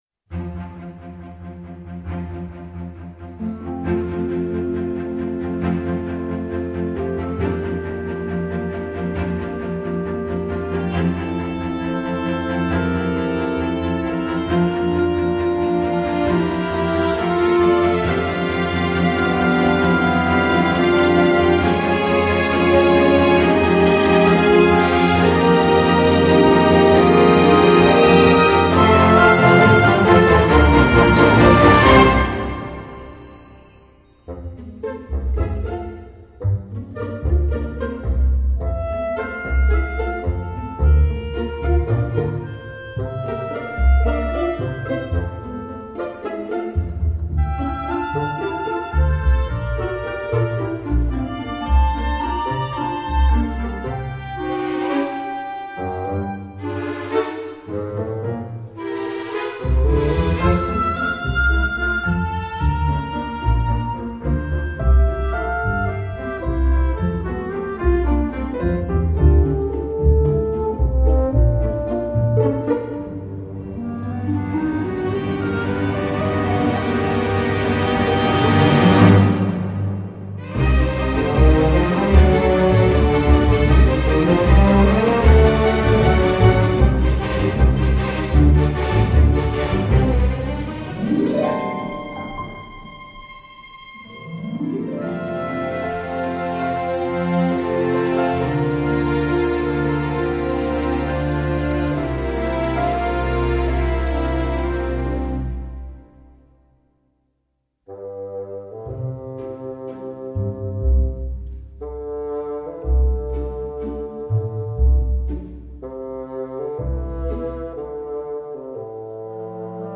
» Belle et espiègle musique de comédie familiale.